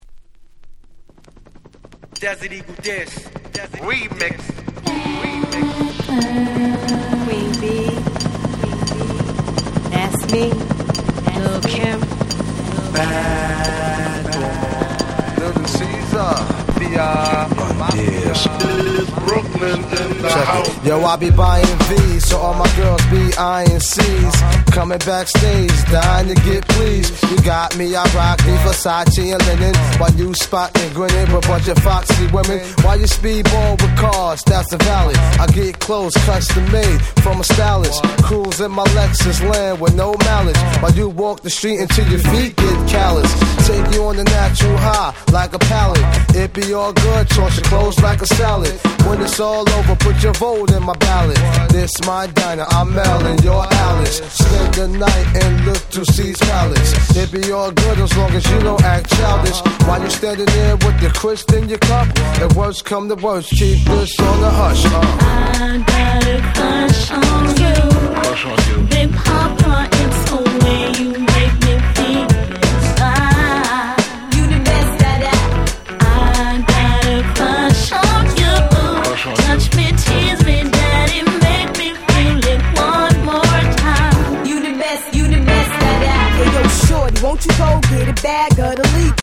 US盤には未収録のRemixもサビが替え歌で最高！！！！